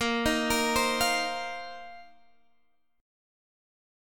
A#sus2sus4 chord